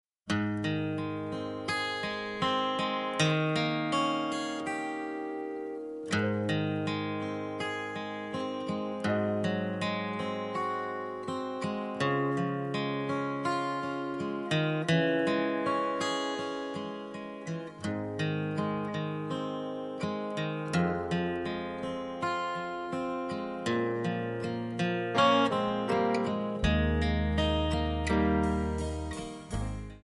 Eb
MPEG 1 Layer 3 (Stereo)
Backing track Karaoke
Country, 2000s